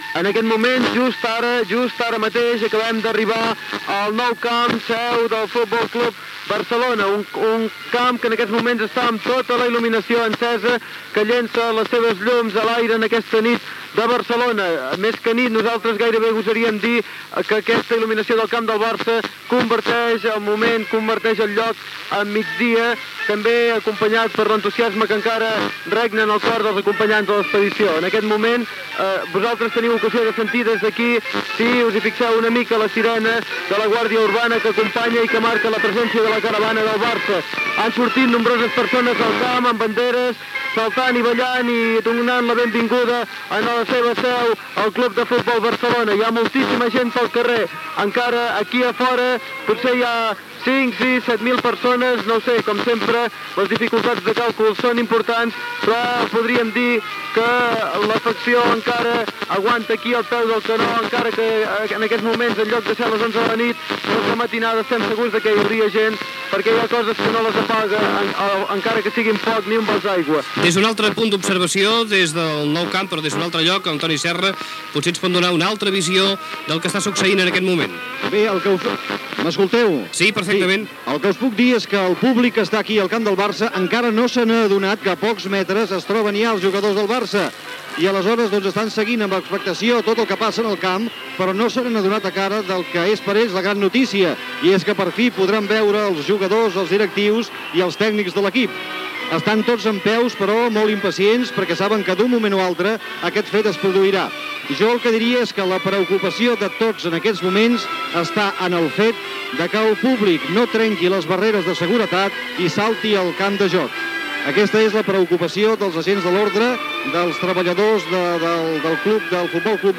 Transmissió de l'arribada del F:C: Barcelona amb la recopa d'Europa de futbol masculí.
Ambient als carrers de Barcelona (Via Laietana)
Informatiu